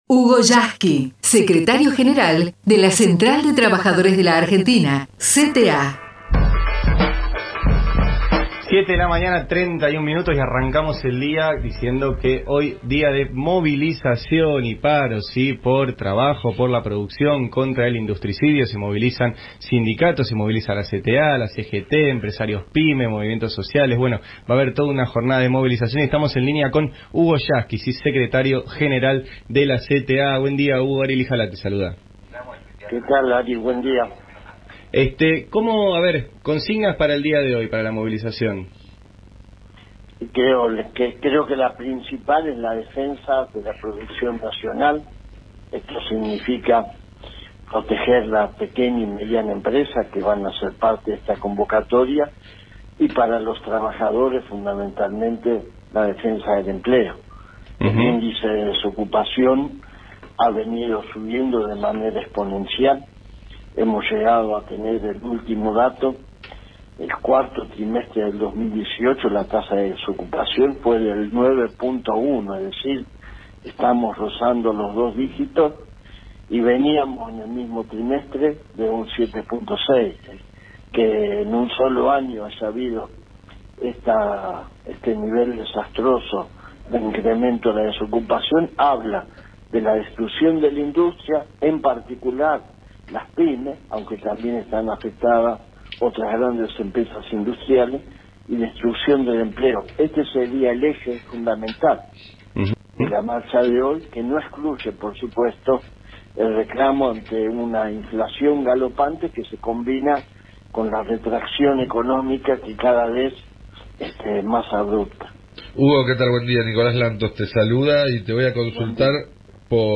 HUGO YASKY entrevistado en El Destape Radio